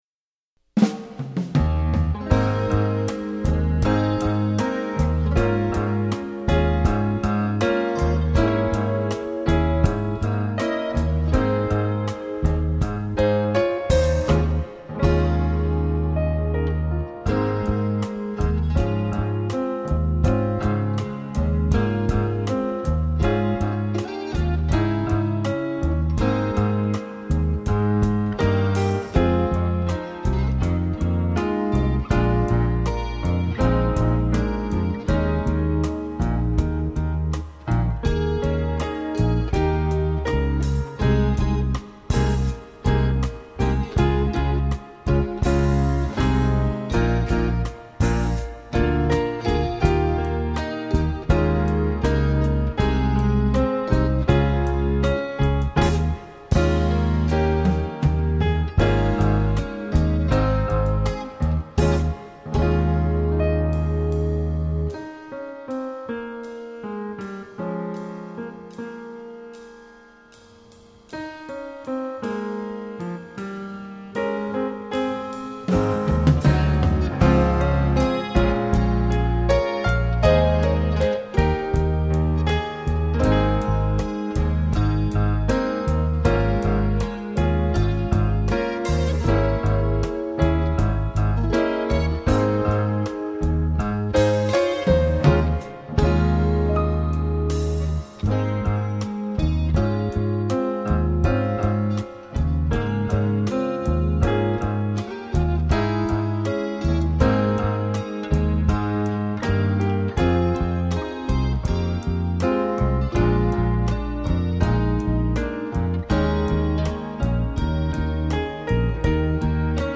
2018-Sr-Vocal-accomp.mp3